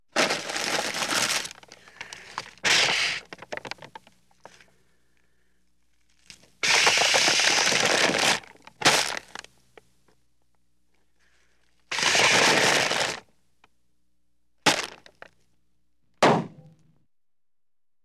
household
Paper Bag Being Blown Up and Popped